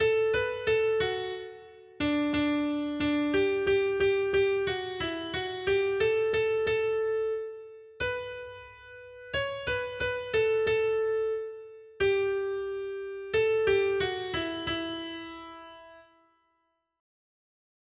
Noty Štítky, zpěvníky ol433.pdf responsoriální žalm Žaltář (Olejník) 433 Skrýt akordy R: Blaze lidu, který si Hospodin vyvolil za svůj majetek. 1.